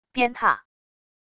鞭挞wav音频